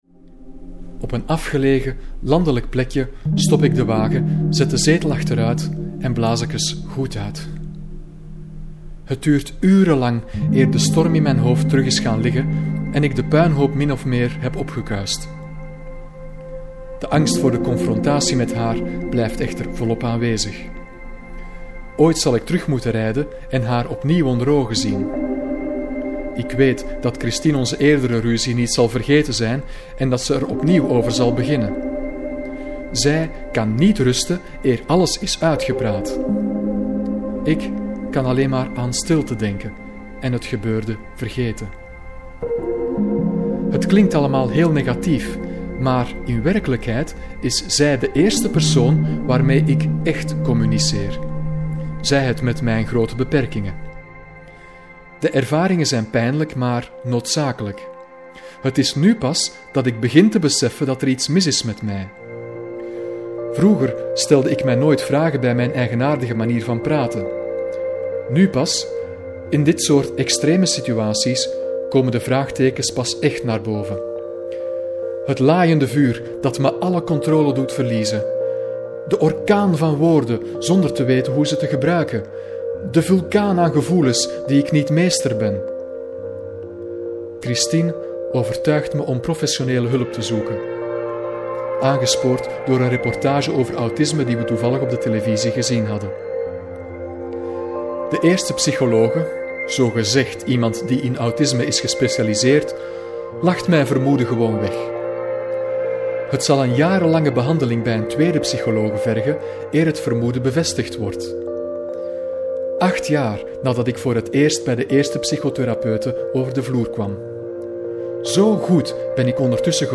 However, most of the work went into creating background music and sound-effects.
Even if you don't understand my Flemish words, I still hope that you enjoy the music.